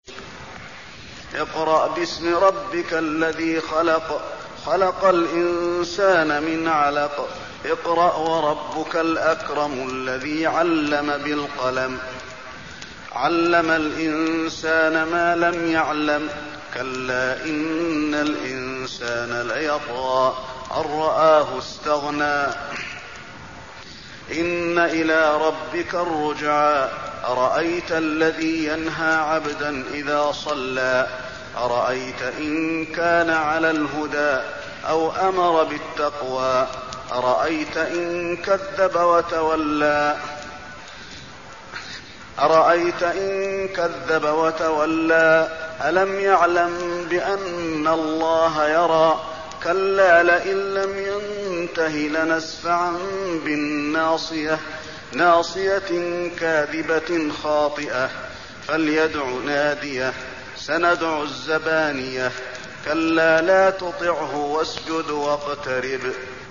المكان: المسجد النبوي العلق